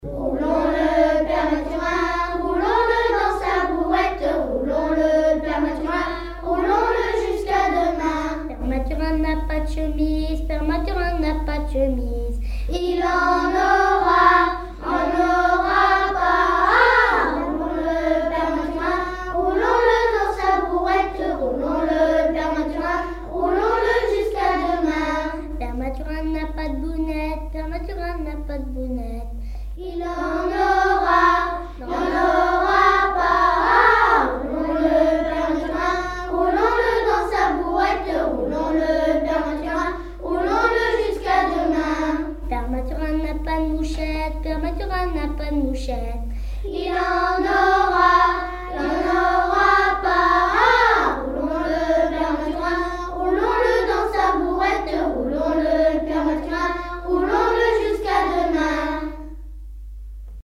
Informateur(s) La grouaïe Tap Dou Païe
danse : ronde : boulangère
Genre énumérative
Pièce musicale inédite